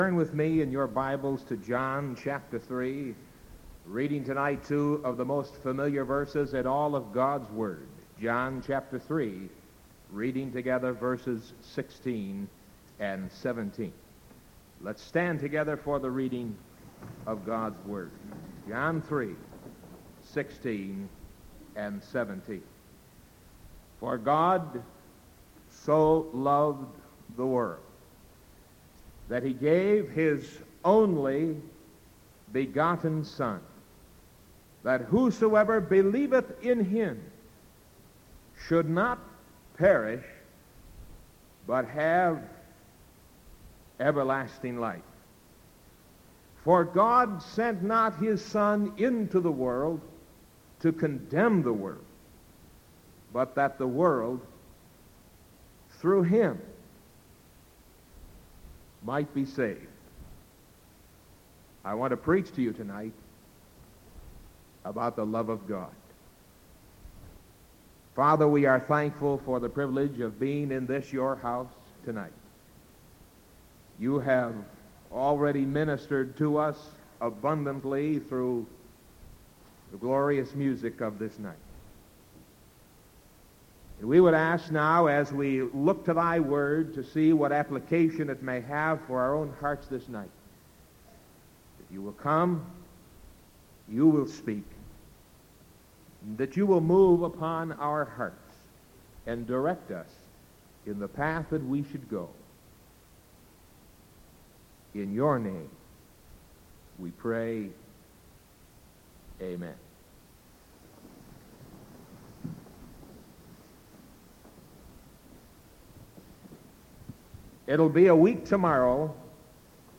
Sermon August 12th 1973 PM